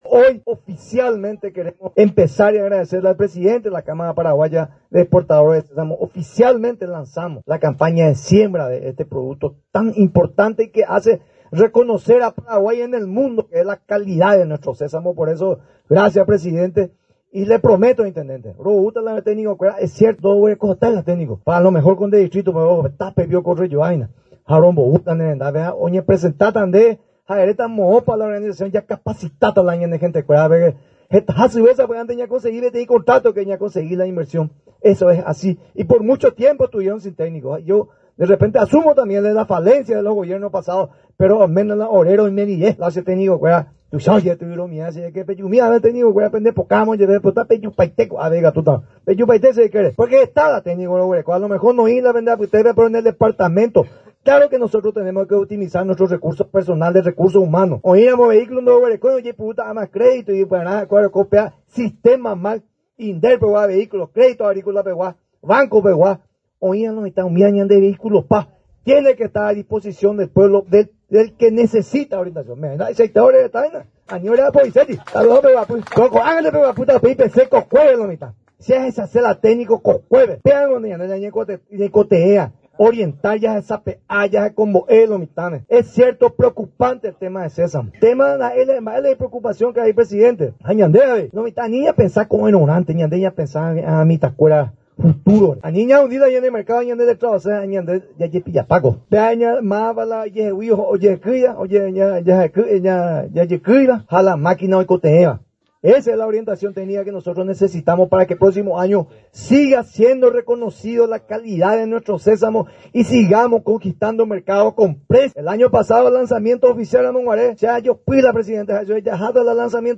Este lunes 07 de octubre, se realizó el lanzamiento de la campaña de siembra de sésamo zafra 2024/2025 en el segundo departamento, que tuvo lugar en la comunidad de Kamba Rembe, distrito de San Vicente Pancholo.
AUDIO: DR. VET CARLOS GIMÉNEZ-MINISTRO DEL MAG